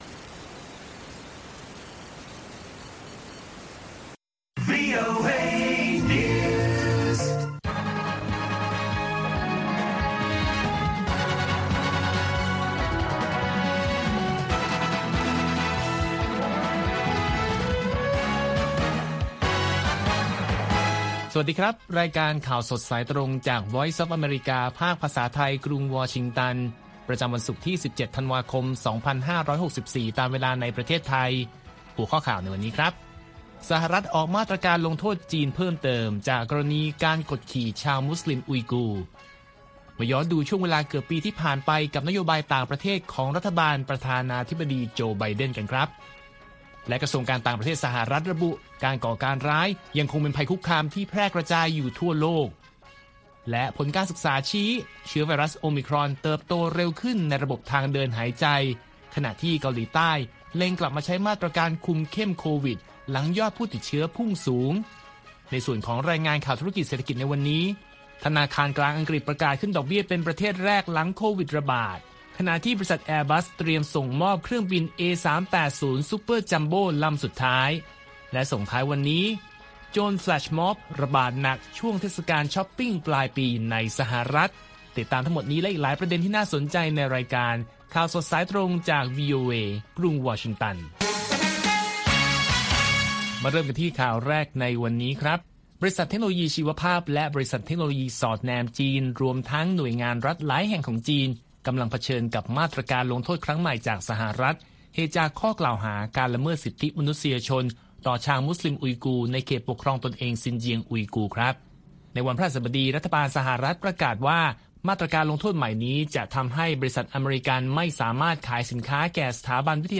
ข่าวสดสายตรงจากวีโอเอ ภาคภาษาไทย ประจำวันศุกร์ที่ 17 ธันวาคม 2564 ตามเวลาประเทศไทย